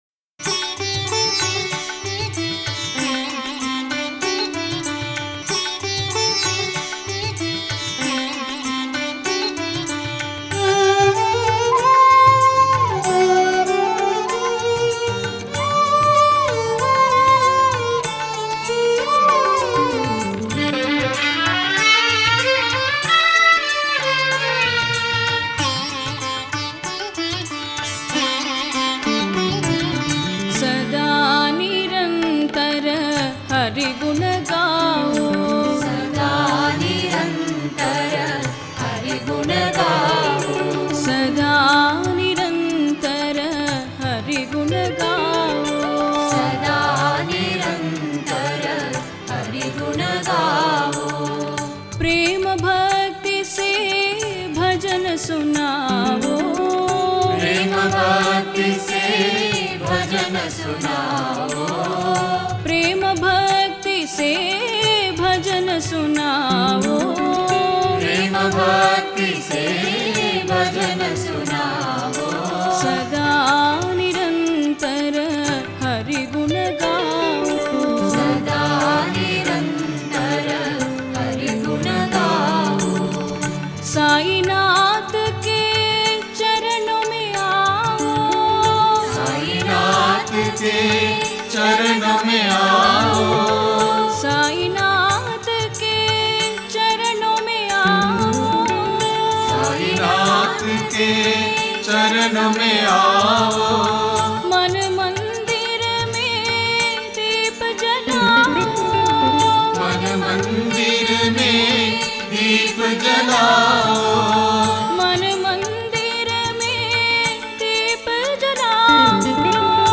Author adminPosted on Categories Sai Bhajans